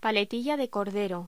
Locución: Paletilla de cordero
voz